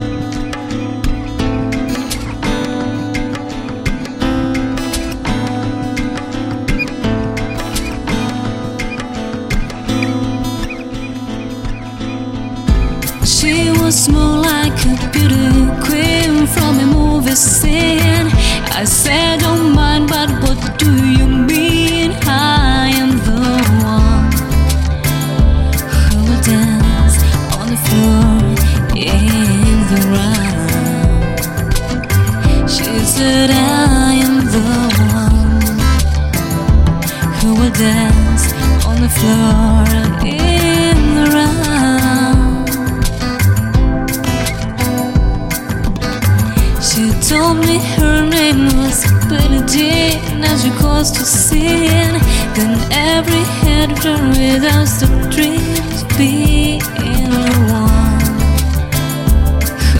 Электронная
Ремиксы в стиле chill-out на такие известные песни